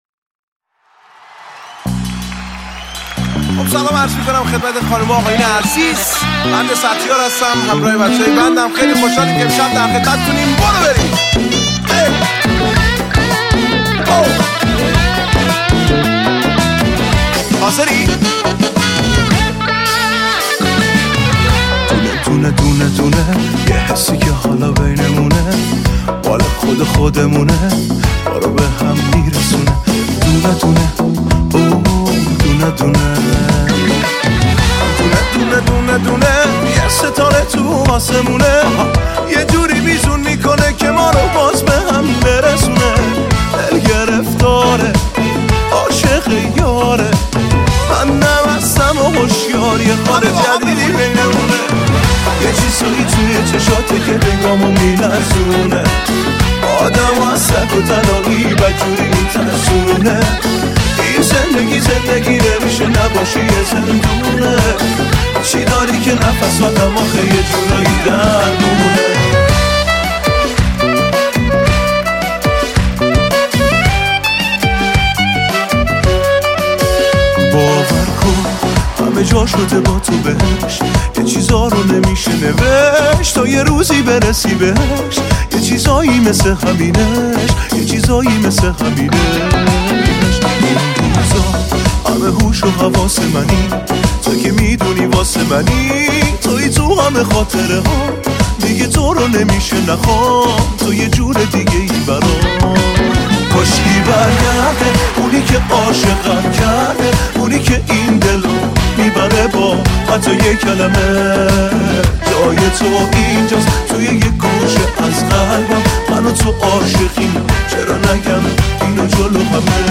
ریمیکس لایو شاد